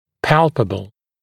[‘pælpəbl][‘пэлпэбл]пальпируемый, прощупываемый